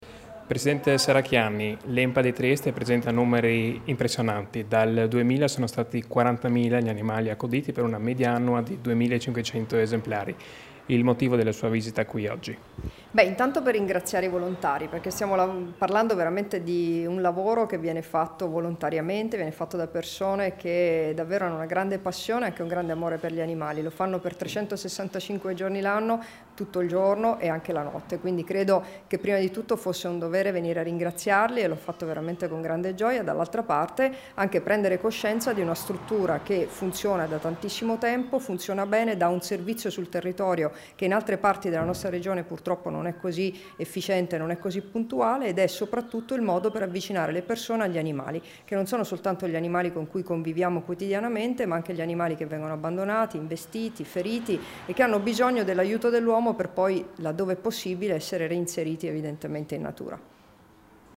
Dichiarazioni di Debora Serracchiani (Formato MP3) [989KB]
a margine della visita all'Ente Nazionale Protezione Animali (ENPA), rilasciate a Trieste il 3 luglio 2017